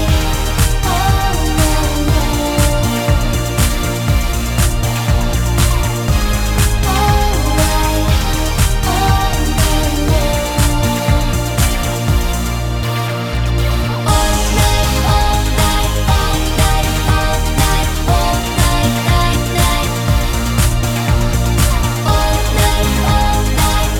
No Rapper Pop (2010s) 3:52 Buy £1.50